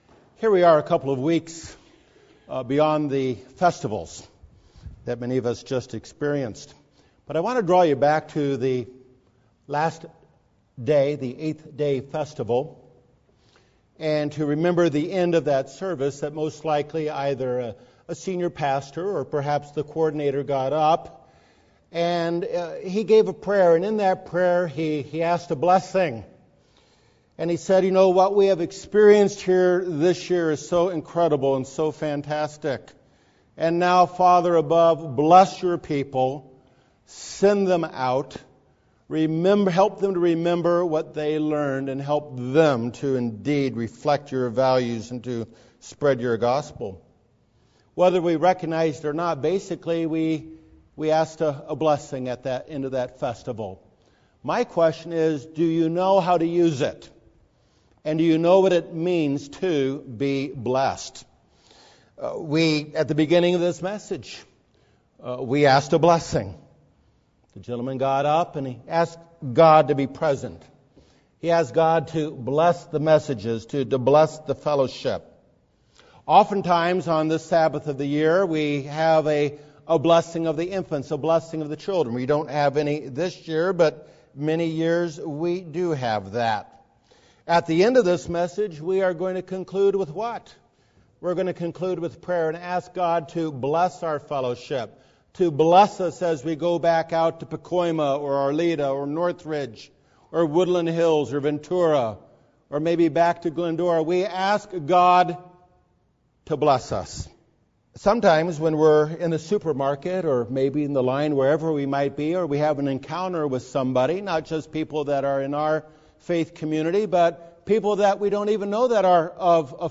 This is a sermon spoken from the heart about blessings we all need and desire.